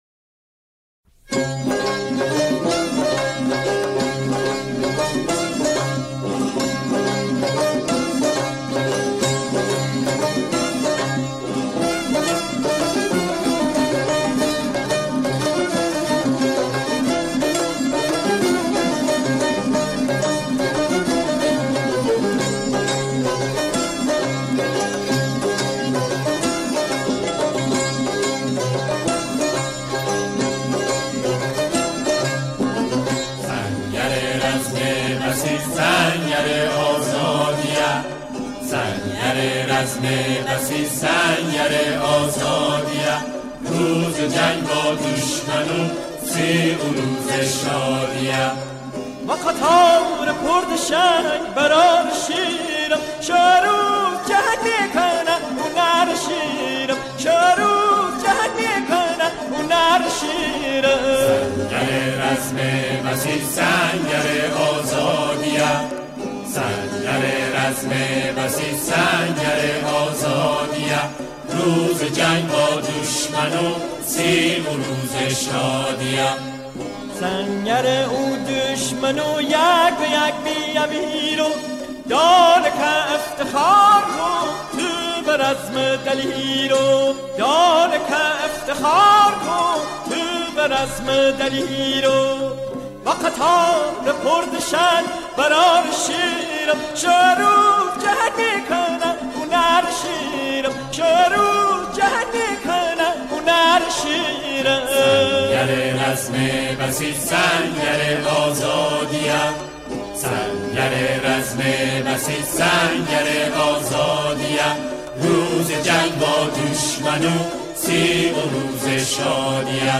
سرود نوستالژی
در این قطعه، آن‌ها، شعری را درباره بسیج می‌خوانند.